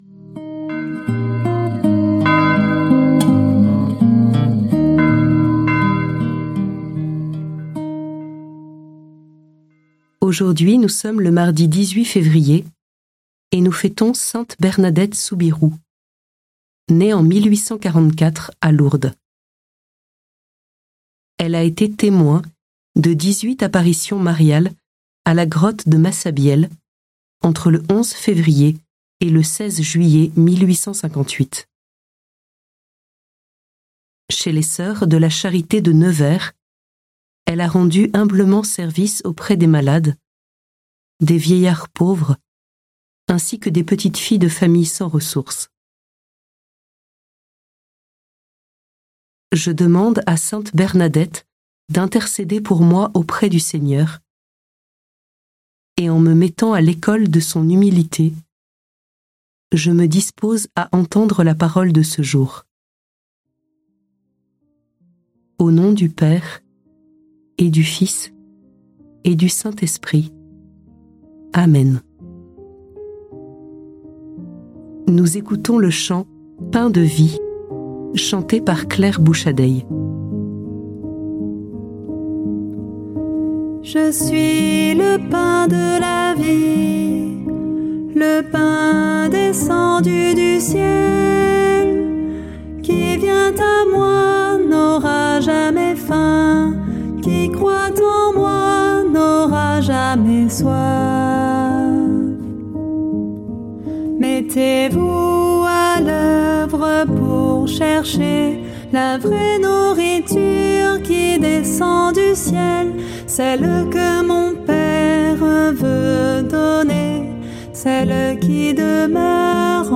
Prière audio avec l'évangile du jour - Prie en Chemin
Musiques